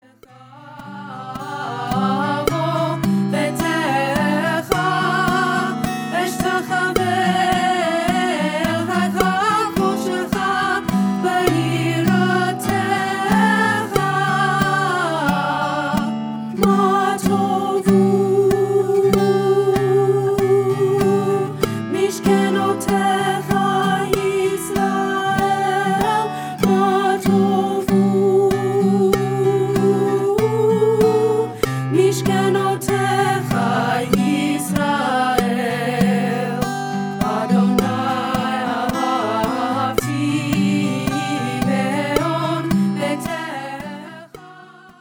Alto rehearsal